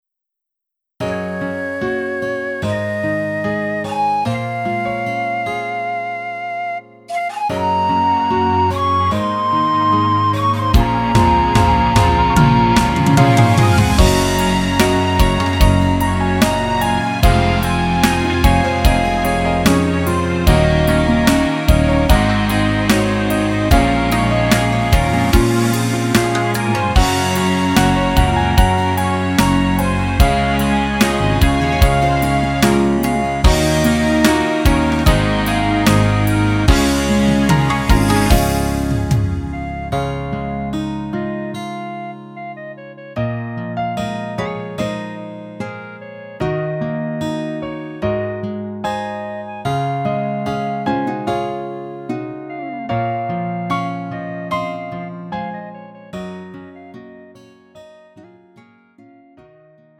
음정 원키 3:59
장르 가요 구분